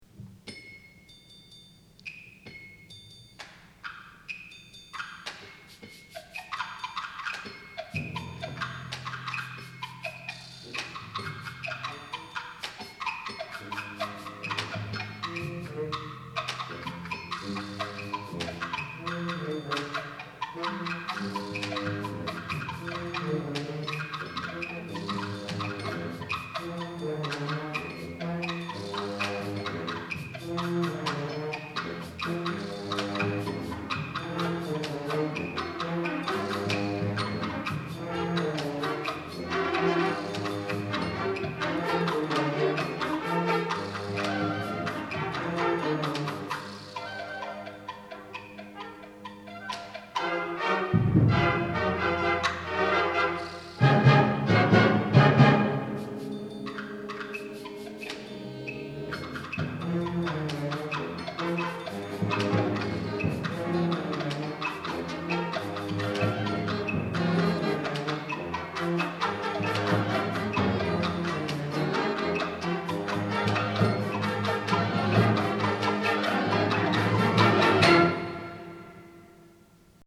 Genre: Band
Timpani (4 drums)